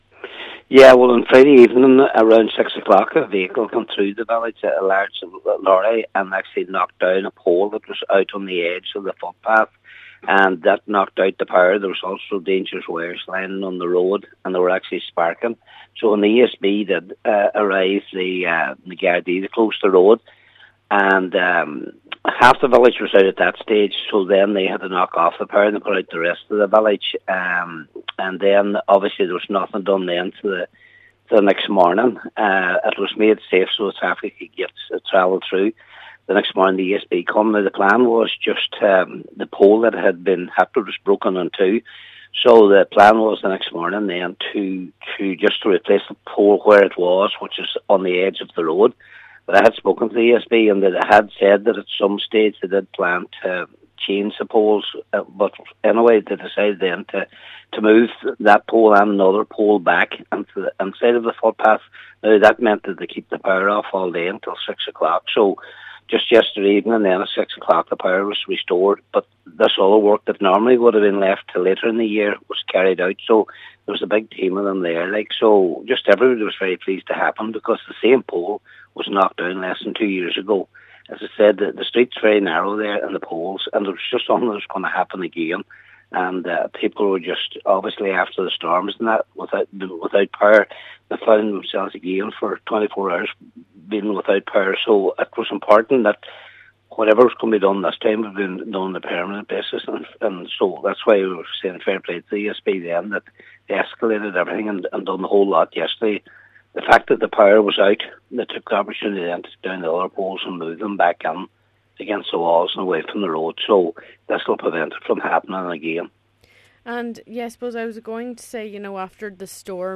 Cllr Patrick McGowan says the work done by the ESB will help prevent incidents like this happening again.